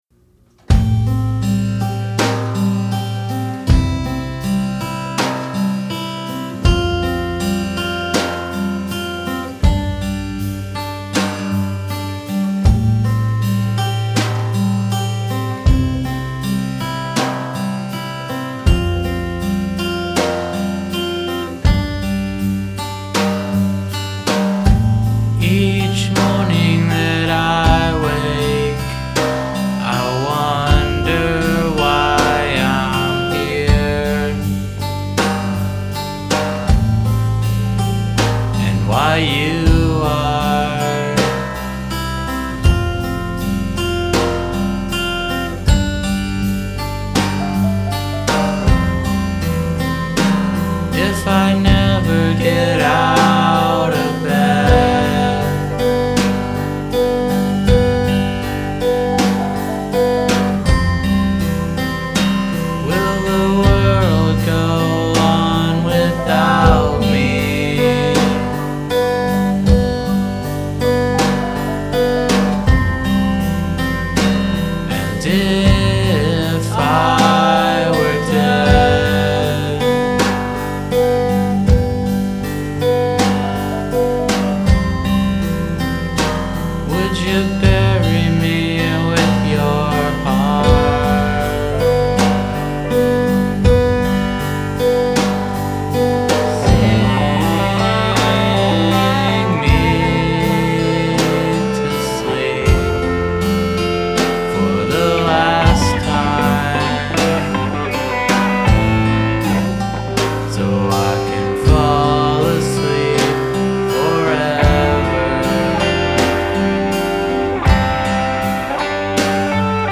I recorded these songs in my home studio in 2009.